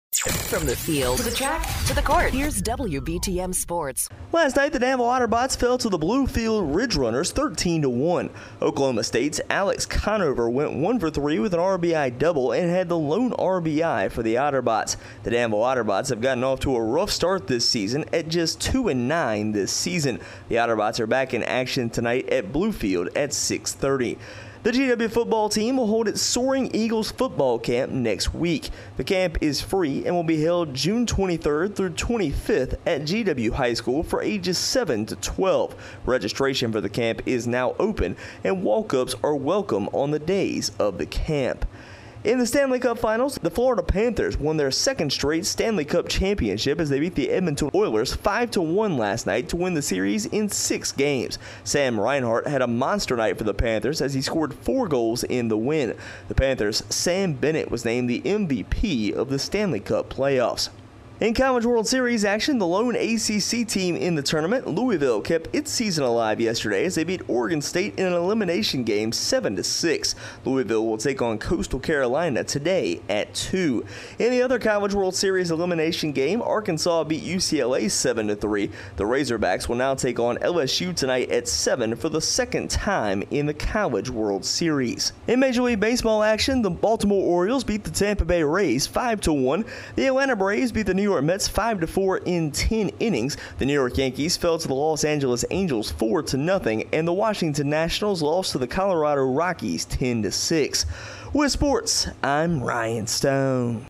Otterbots fall to Bluefield, GW Football to Host Youth Football Camp and More in Our Local Sports Report